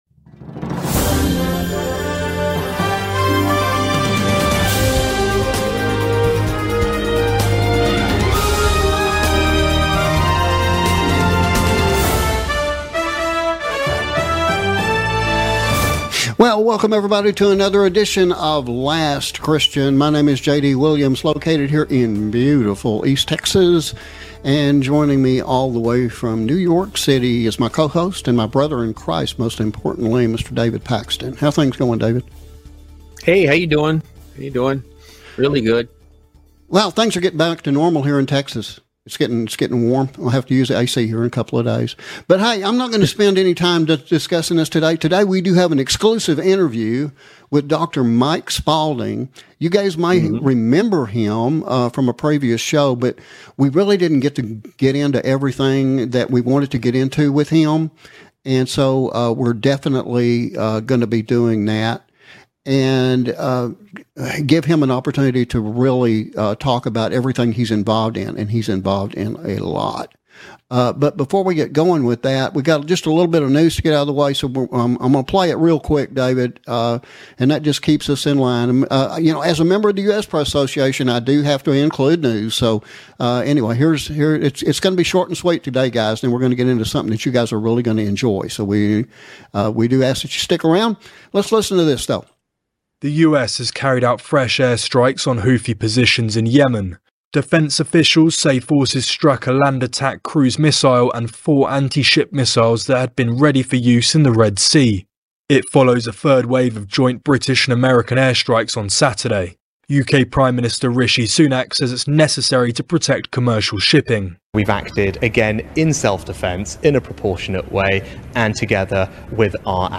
KRRB Revelation Radio / Exclusive Interview